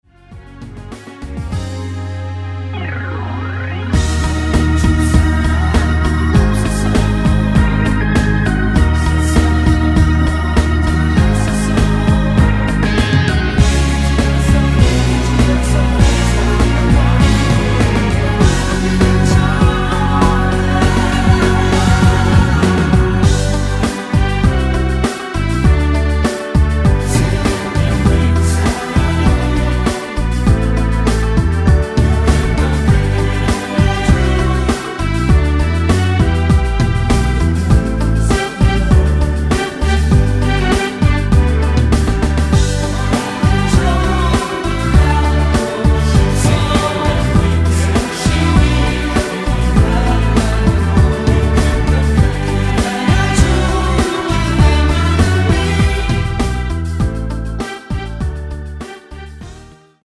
(-1) 내린 코러스 포함된 MR 입니다.(미리듣기 참조)
F#
◈ 곡명 옆 (-1)은 반음 내림, (+1)은 반음 올림 입니다.
앞부분30초, 뒷부분30초씩 편집해서 올려 드리고 있습니다.
중간에 음이 끈어지고 다시 나오는 이유는